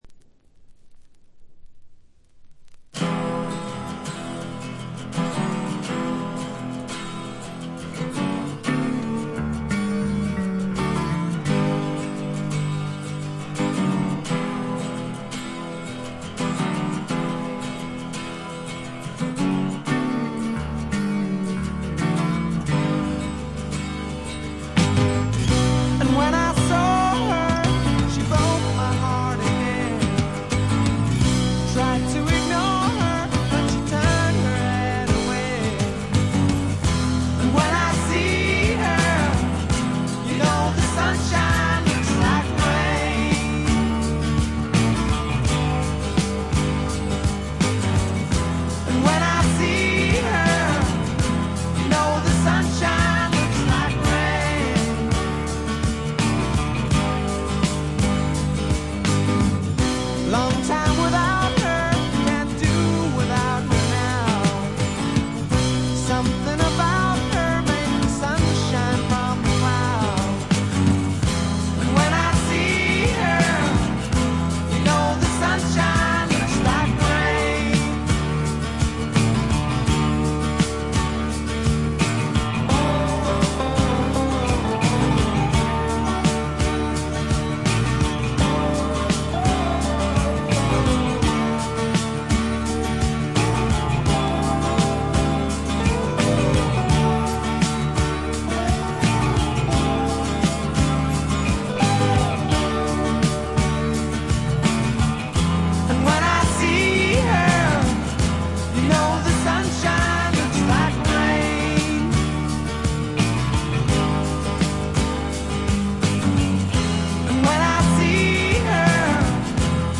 部分試聴ですがチリプチ少々。
当時の流行であったスワンプと英国流ポップを合体させたような素晴らしい作品に仕上がっています。
試聴曲は現品からの取り込み音源です。
Recorded at Dick James Studio, London.